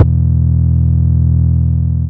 Index of /Antidote Advent/Drums - 808 Kicks
808 Kicks 01 E.wav